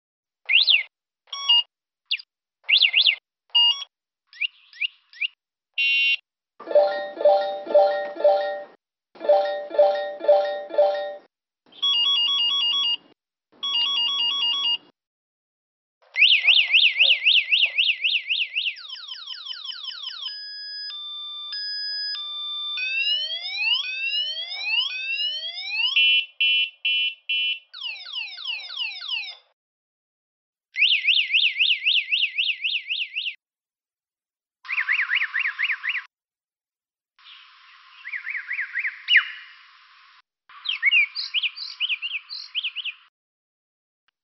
Китайские черные дрозды включили в «репертуар» сигнализацию электромопедов
Группа биологов и экологов из Нанкинского университета (КНР) заметила, что черные дрозды (Turdus mandarinus), обитающие неподалеку от их вуза, подражают противоугонной сигнализации электрических мопедов — одного из самых распространенных видов транспорта в Китае. Специалисты решили оценить, насколько точны пернатые в имитации.